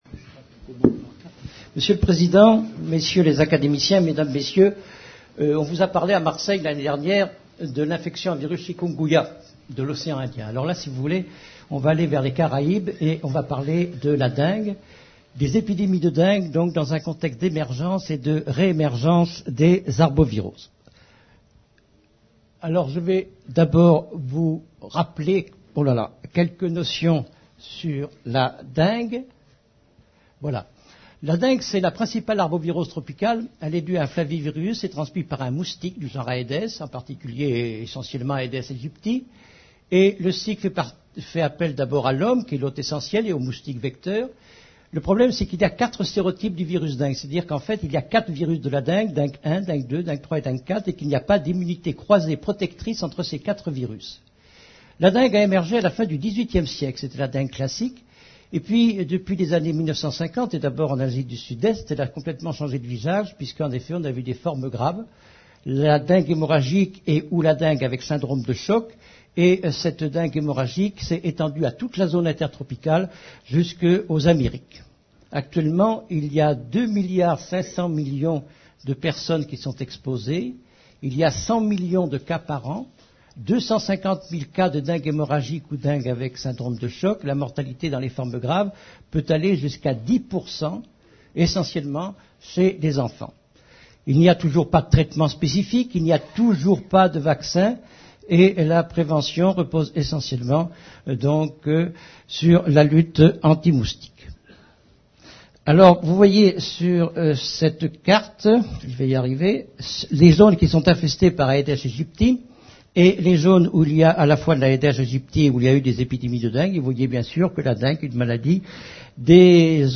Mais quelles sont les causes de cette réémergence des arboviroses ? et la France sera-t-elle toujours épargnée ? La conférence a été donnée à l'Université Victor Segalen Bordeaux 2 à l’occasion de la séance délocalisée de l’Académie Nationale de Médecine « Bordeaux, Porte Océane » le 15 avril 2008.